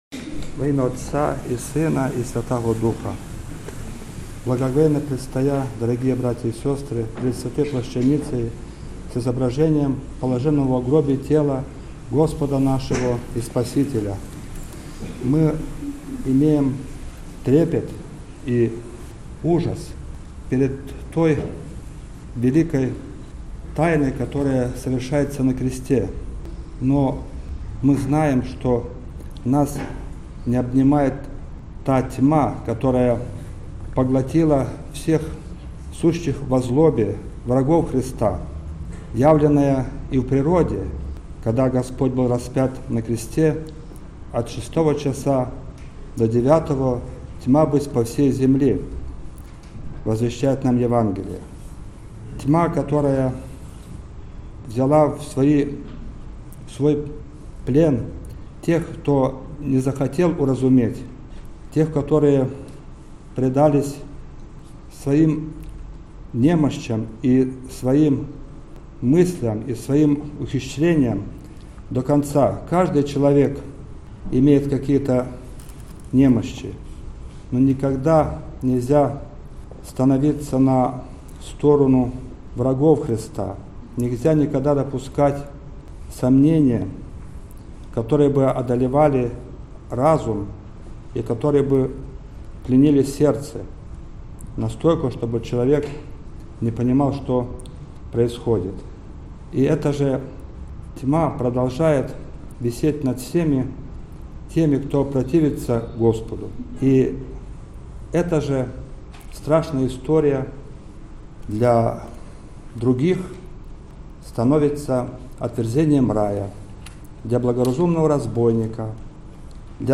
Вынос-плащаницы.mp3